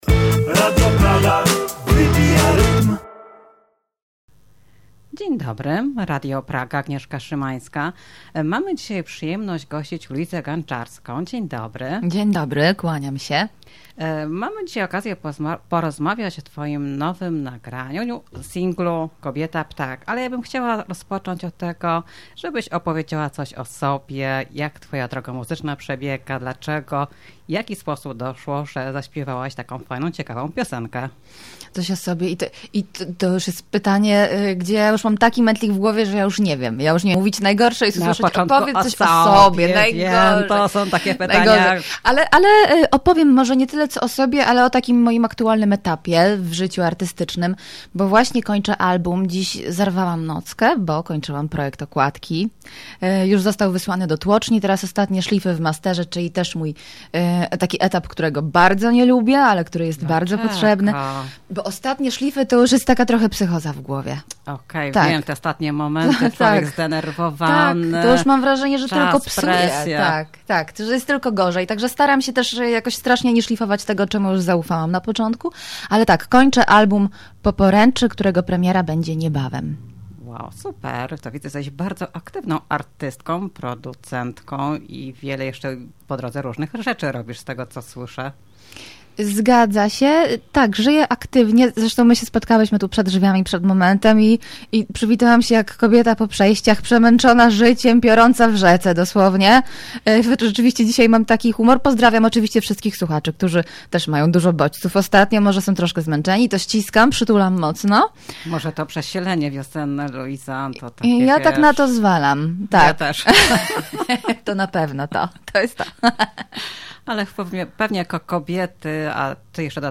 Rozmowa jest już dostępna do odsłuchu na stronie internetowej Radia Praga.